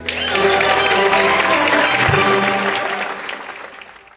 fanfare-1.ogg